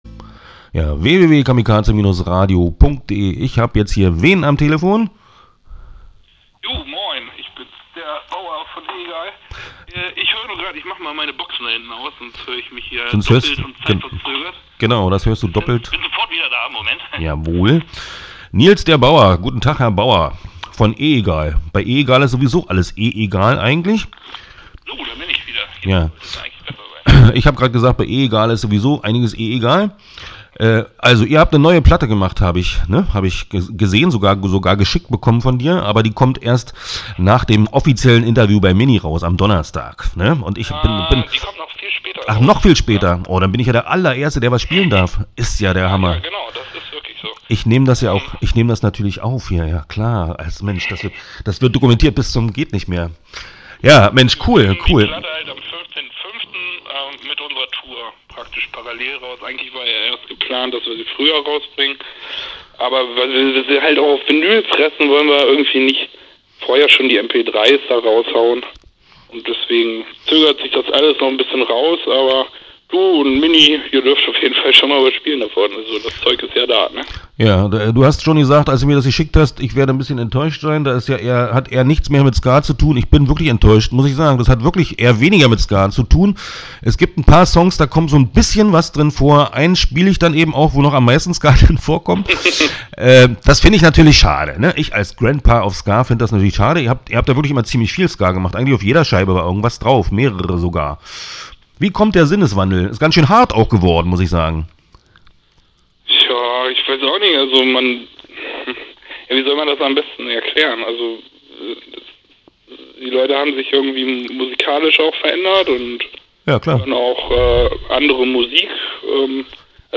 Interviews
sprach in seiner Sendung kurz